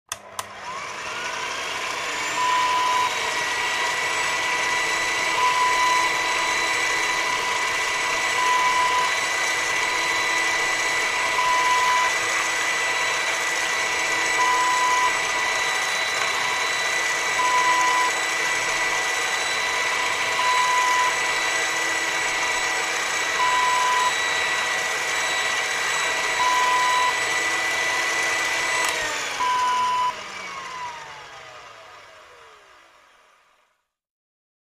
Kitchen Hand Mixer Sound Effect Wav
Description: Kitchen hand mixer switches on, runs and switches off
Properties: 48.000 kHz 16-bit Stereo
A beep sound is embedded in the audio preview file but it is not present in the high resolution downloadable wav file.
hand-mixer-preview-1.mp3